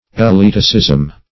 \E`le*at"i*cism\